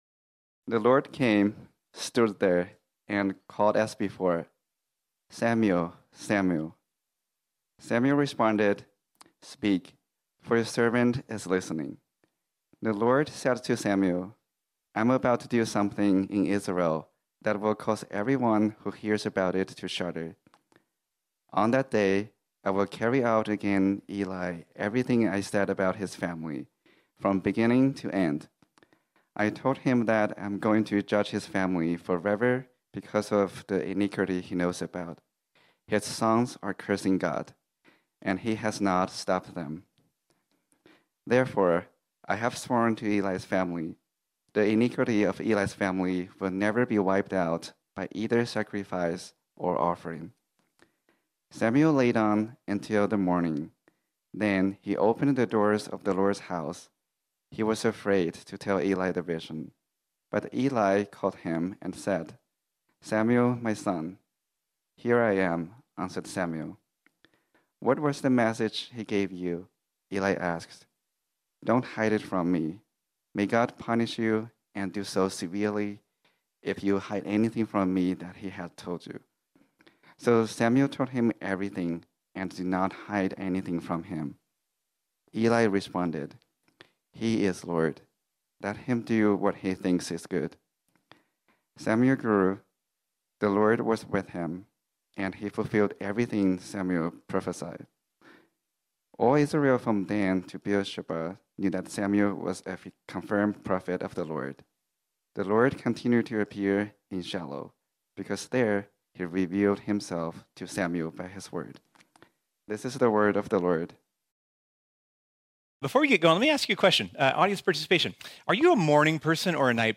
This sermon was originally preached on Sunday, April 23, 2023.